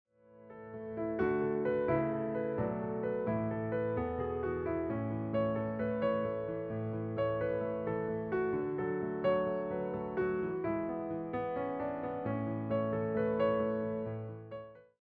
interpreted through solo piano.